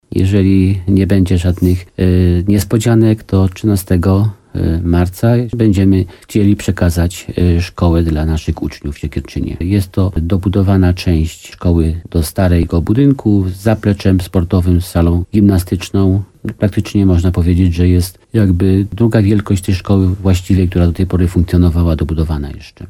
W programie Słowo za Słowo w radiu RDN Nowy Sącz wójt Jan Skrzekut powiedział, że nowa szkoła w Siekierczynie ruszy za niecałe dwa miesiące.